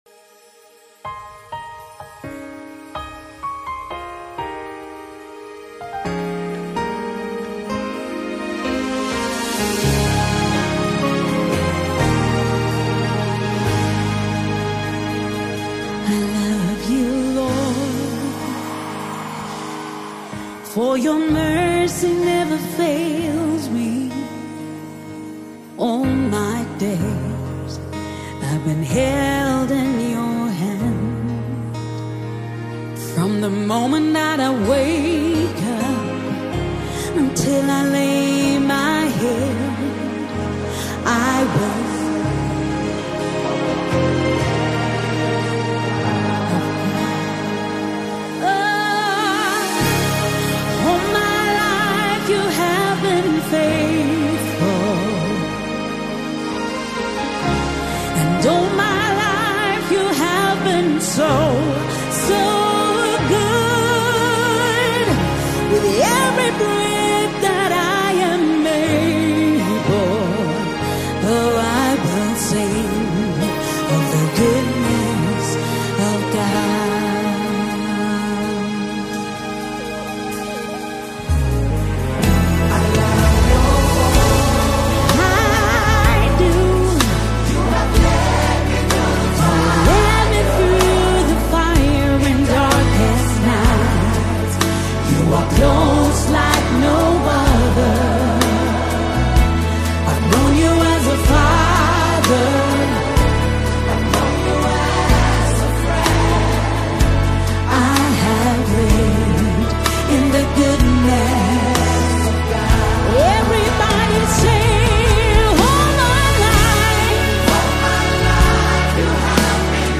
powerful worship song